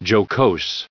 added pronounciation and merriam webster audio
1636_jocose.ogg